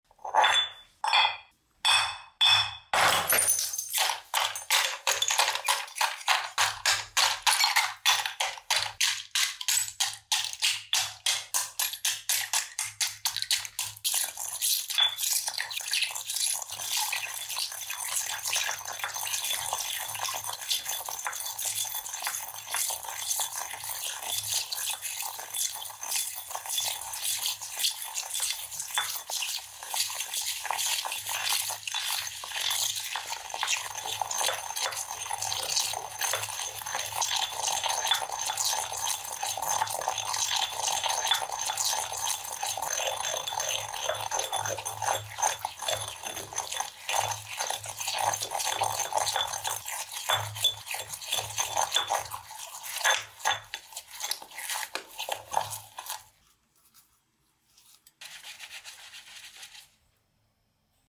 Upload By ASMR videos
Oddlysatisfying crushing Coca Cola bottle